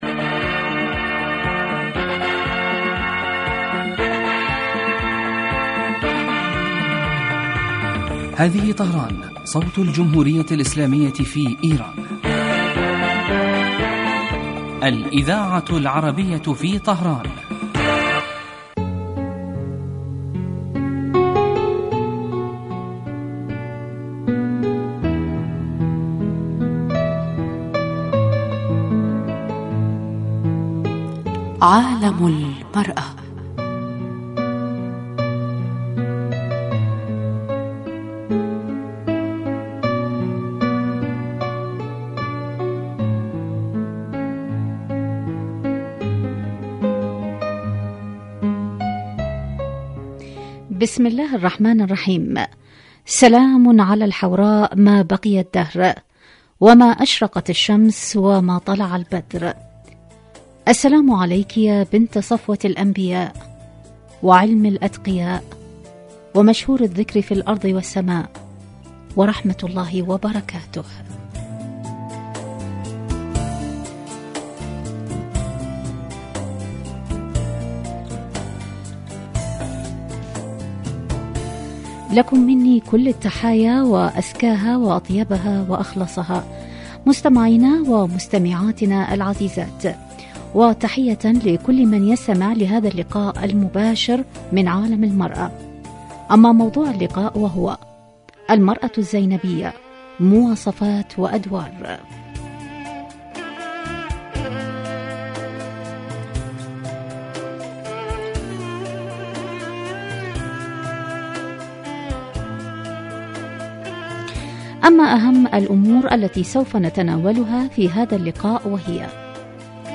من البرامج الناجحة في دراسة قضایا المرأة فی العالمین الإسلامي و العربي ومعالجة ما لها من مشکلات و توکید دورها الفاعل في تطویر المجتمع في کل الصعد عبر وجهات نظر المتخصصین من الخبراء و أصحاب الرأي مباشرة علی الهواء